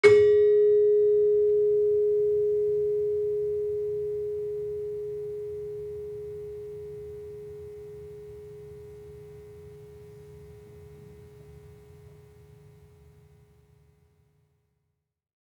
Gender-3-G#3-f.wav